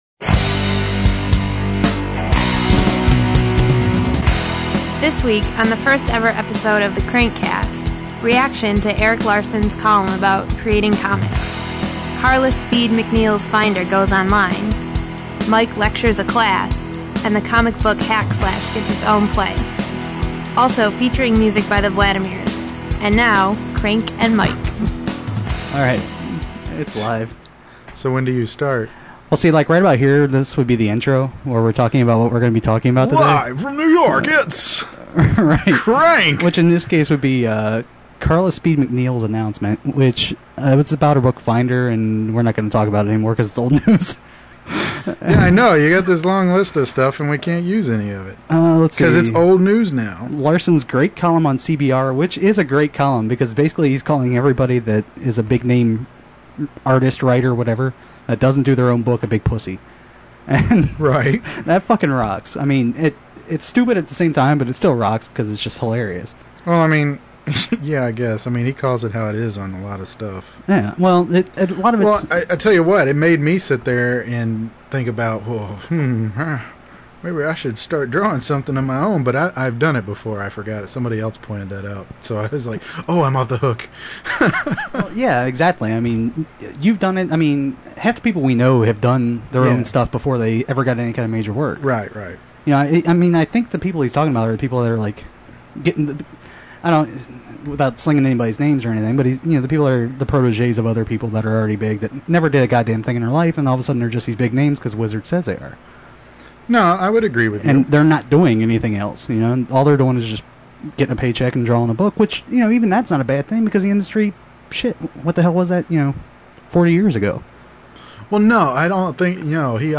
It’s pretty rough.
For some reason we kept saying cool over and over.